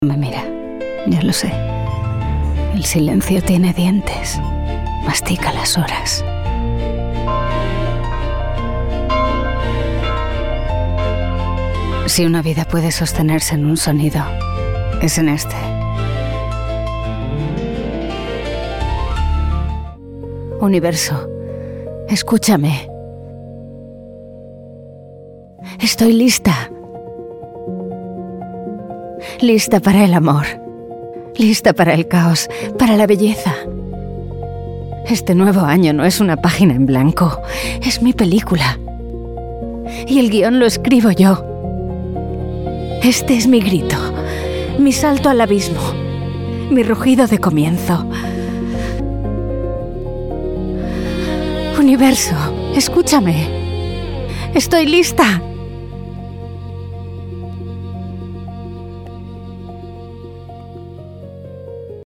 Veelzijdig, Zakelijk, Commercieel, Vriendelijk, Natuurlijk